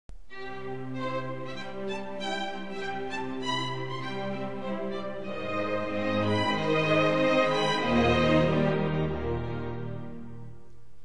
Pierino-archi.wma